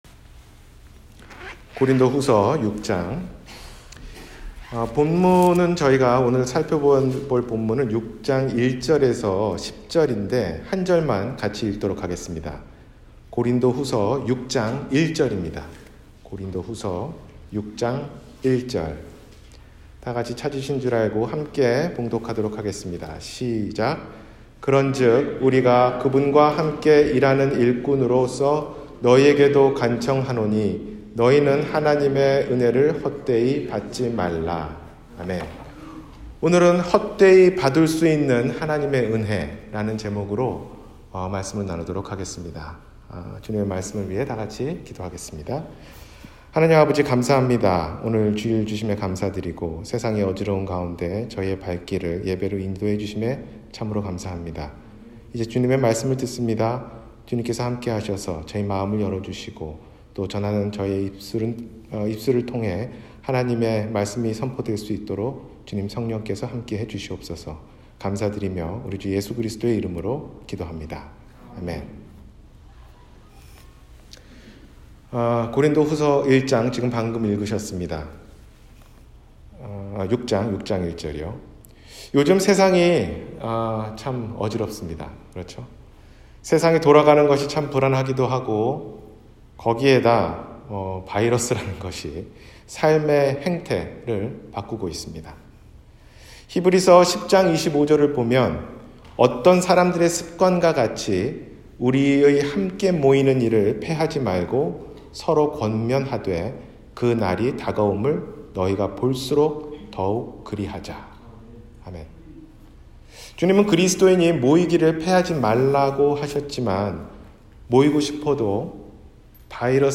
헛되이 받을 수 있는 은혜 – 주일설교